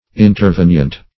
Search Result for " intervenient" : The Collaborative International Dictionary of English v.0.48: Intervenient \In`ter*ven"ient\, a. [L. interveniens, p. pr. of intervenire.]